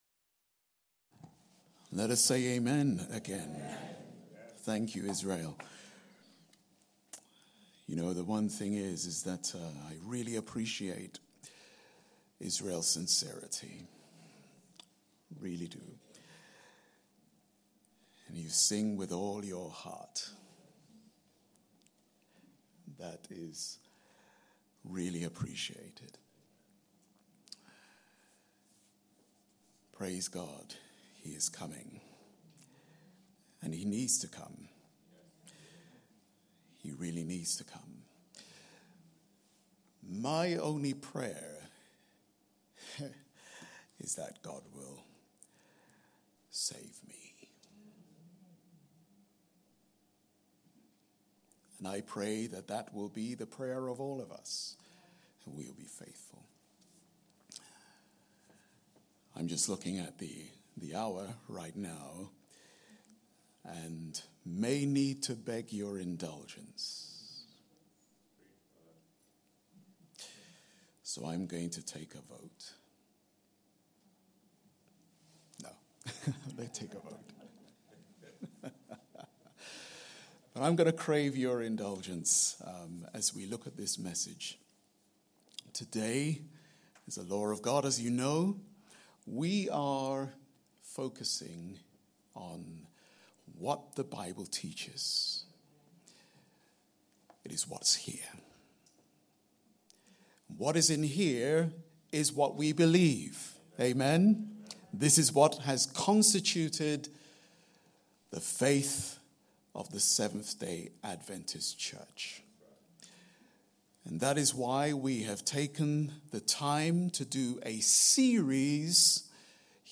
Family Service Recordings